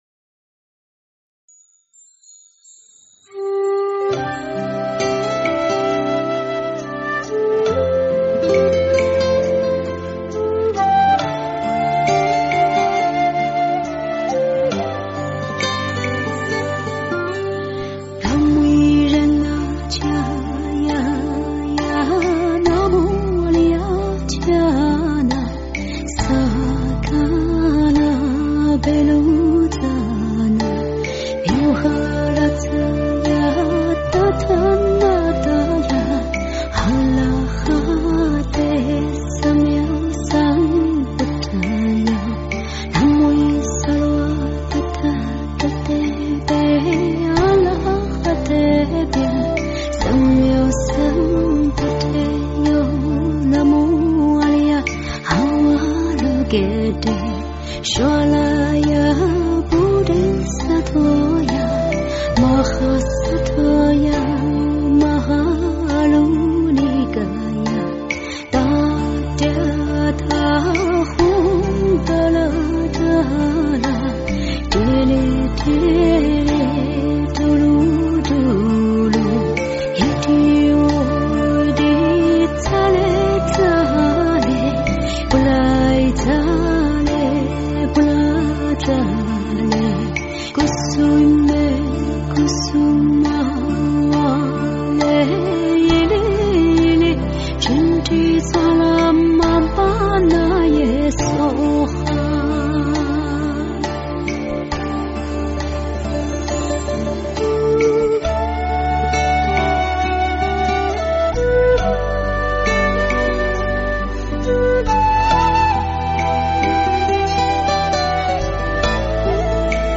标签: 佛音诵经佛教音乐